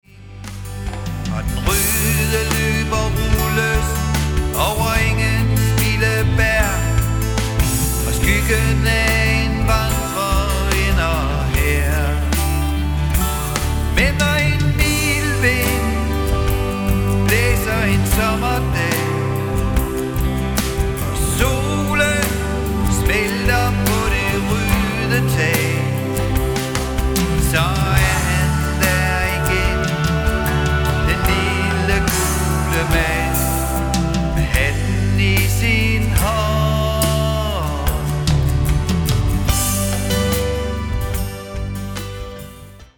Trommer, percussion og sang.
Bas og sang.
Guitar, keyboards og sang.
El-guitar og sang.